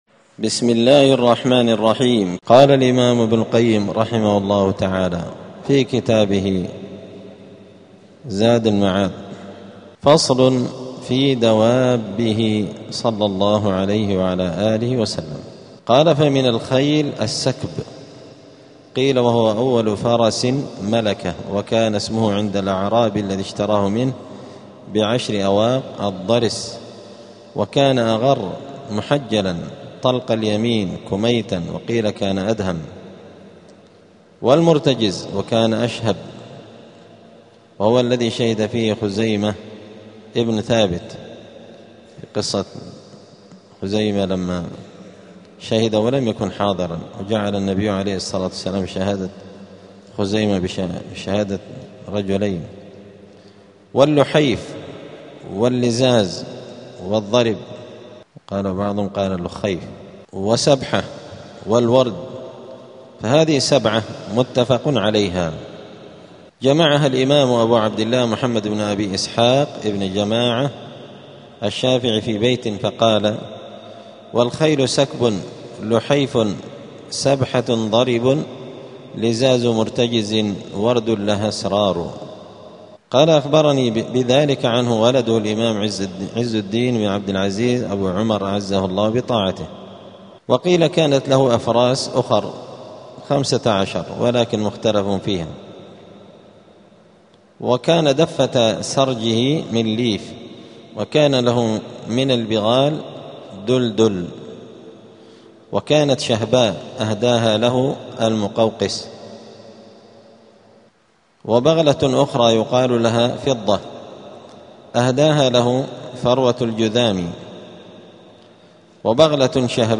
*الدرس الثالث والعشرون (23) {ﻓﺼﻞ ﻓﻲ دوابه ﺻﻠﻰ اﻟﻠﻪ ﻋﻠﻴﻪ ﻭﺳﻠﻢ}.*
دار الحديث السلفية بمسجد الفرقان قشن المهرة اليمن